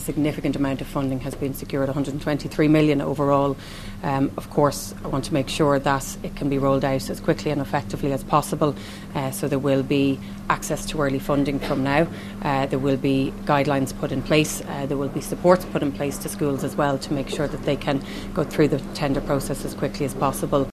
Education Minister Helen McEntee says it will remove a major barrier to education for many families: